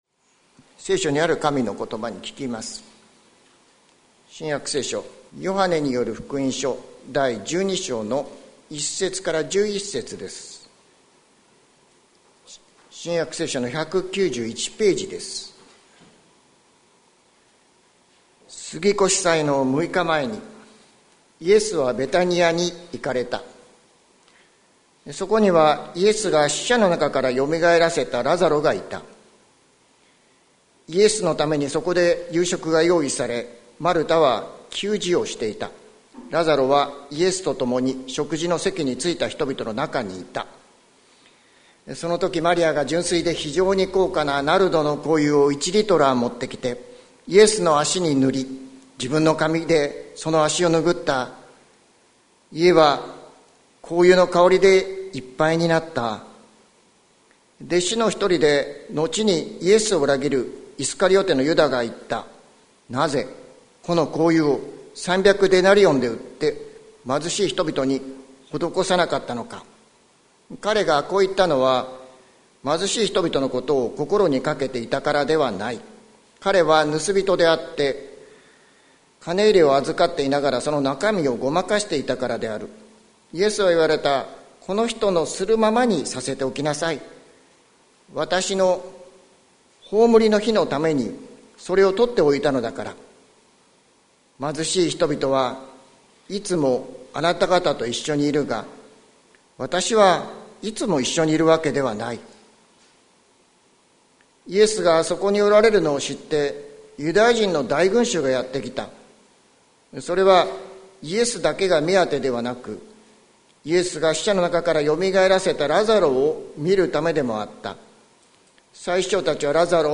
2023年02月19日朝の礼拝「献げよう、主に愛を」関キリスト教会
説教アーカイブ。